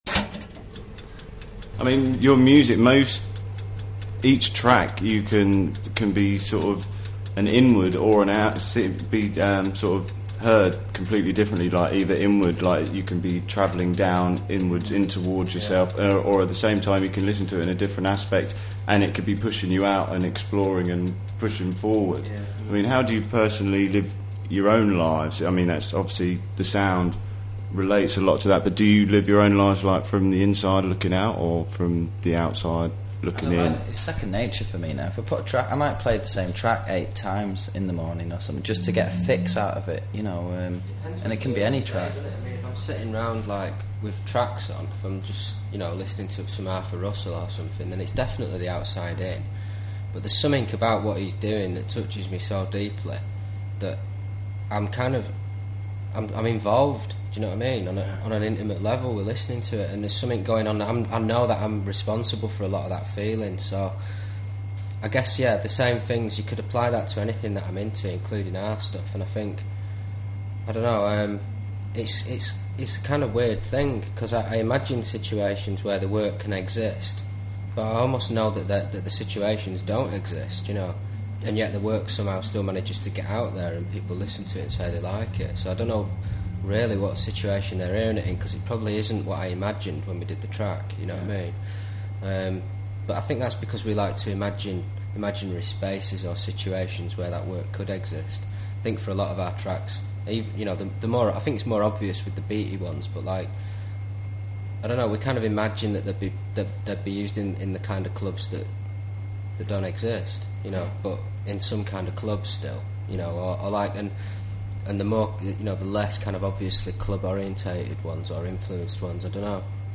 Autechre___2005_07_XX_Toazted.com_interview___2.mp3